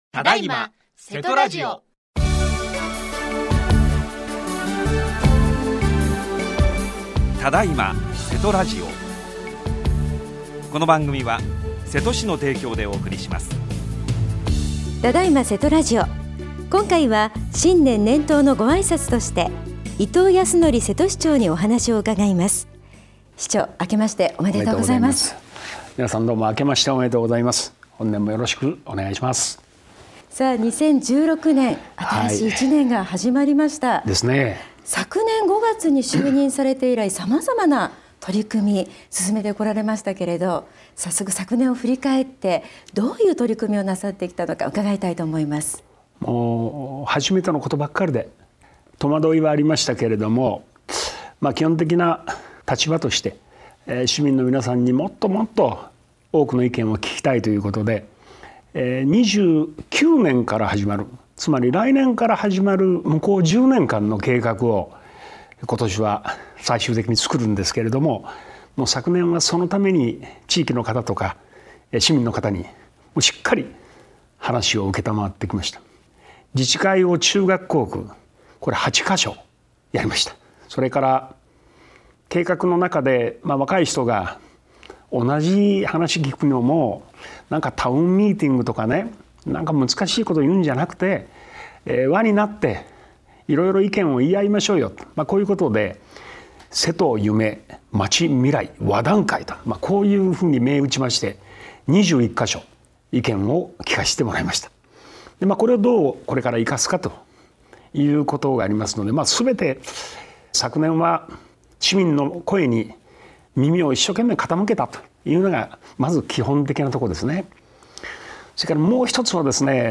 只今！せとラジオ 今回は新年、年頭のご挨拶として伊藤保德瀬戸市長にお話を伺いました。
特別番組 | radiosanq-hp | 2016年1月1日 9:40 AM